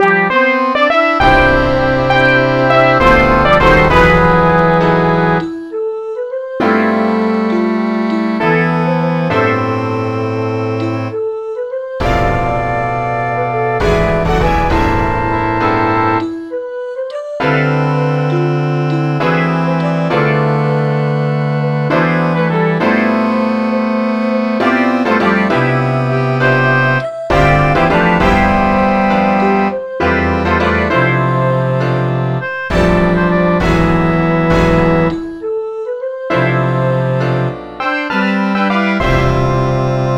c. Liederen met orkestbegeleiding
(fluit, clarinetten, trompet, slagwerk,piano, gitaar, accordeon, violen, altviool, cello, contrabas). -
Ik ben van den buiten (orkest)- Jo van Maas.mp3